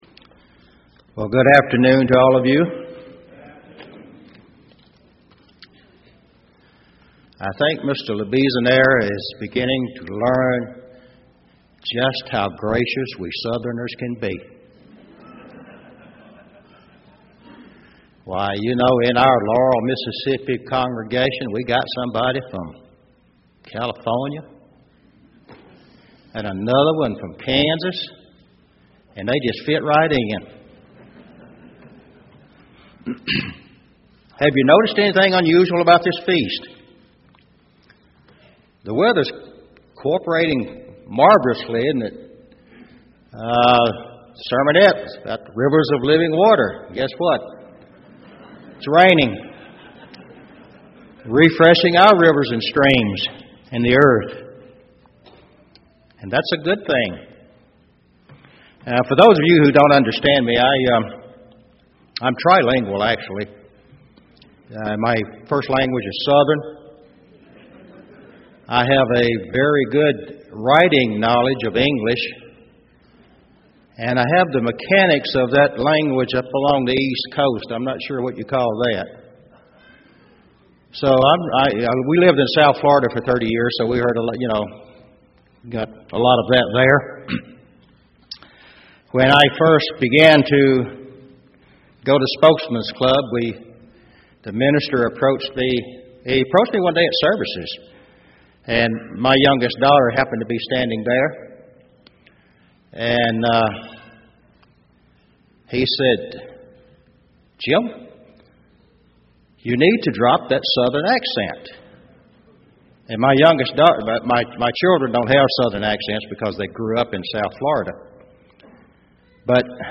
This sermon was given at the Gatlinburg, Tennessee 2013 Feast site.